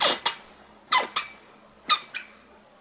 椅子的挤压和呻吟 " 椅子的挤压
描述：一把挤压的旧办公椅，在旧货店买的。舒适，但声音很大。用索尼IC录音机录制，并对嘶嘶声进行过滤。一系列短而高的尖叫声或挤压声。
Tag: 效果 呻吟 呻吟 LOFI squeek squeeky squeel